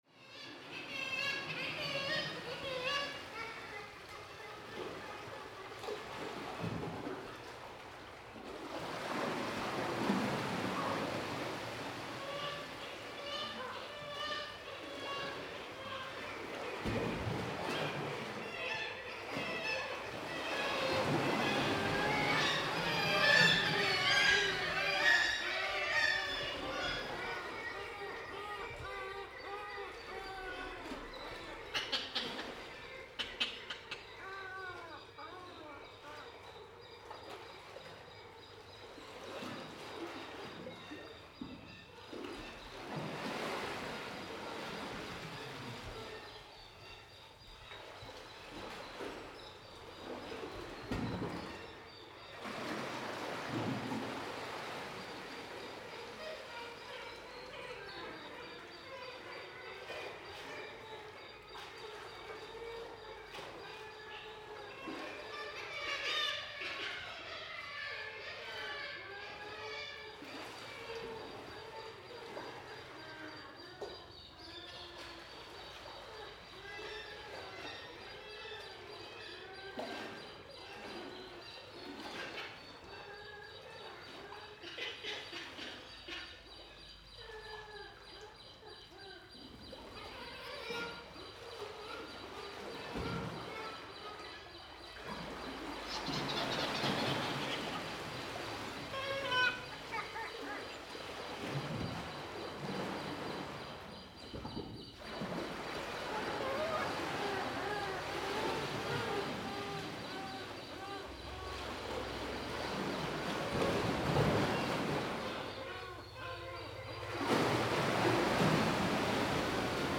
The tourist traffic was very low because of COVID19 so it gave me the opportunity to record places which have been impossible to record without traffic noise and human voices during the last 10 years.
During the summer time the habitats of these cliffs are mainly a Kittiwake but most of them were gone to the sea this weekend so the cliffs were quieter than one month earlier. I decided to record in one of the fissures named Eystrigjá. Eystrigjá is a rather narrow and one of those who have a natural stone bridge.